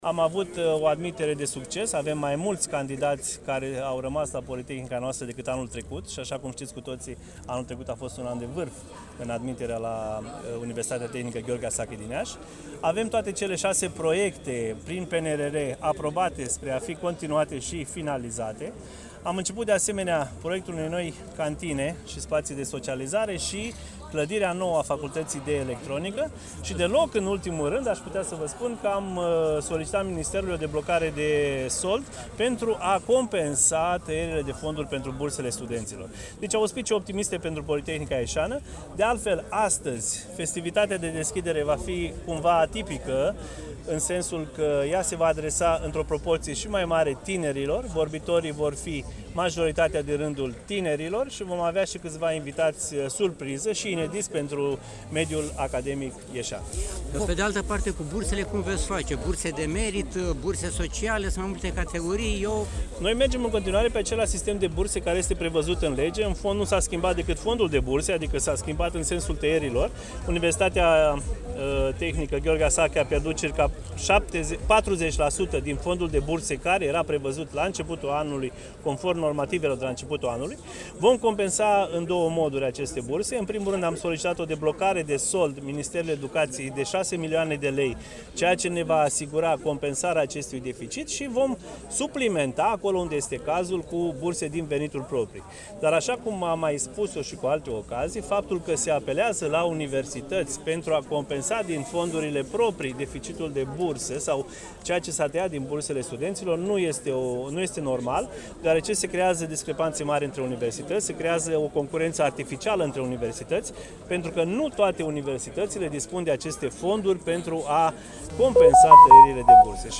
Universitatea Tehnică „Gheorghe Asachi” din Iași (TUIASI) a deschis oficial anul universitar 2025–2026 luni, 29 septembrie 2025, după ora 11:00, în Parcul „Prof. Cezar Oprișan”, situat între căminele T9–T11 din campusul Tudor Vladimirescu.